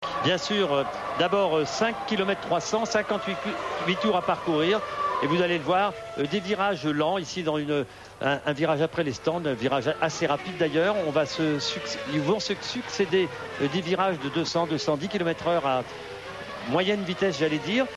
sur TF1